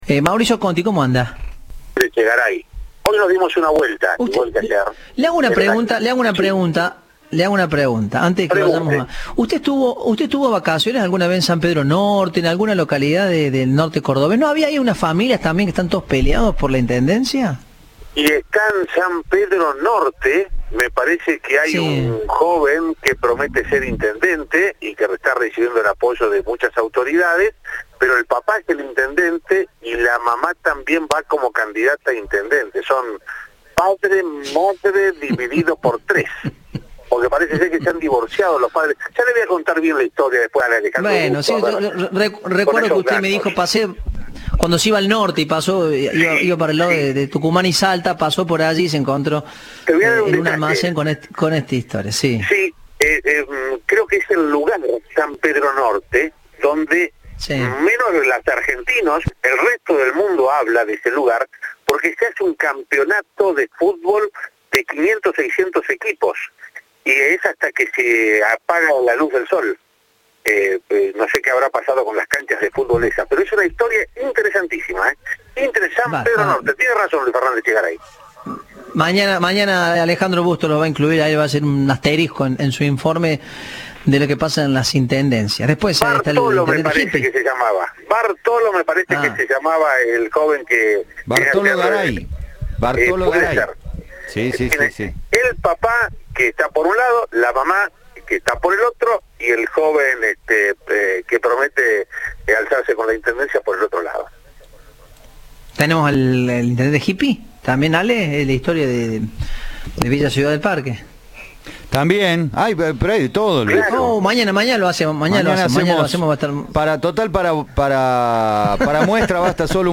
opinó al respecto en diálogo con Cadena 3.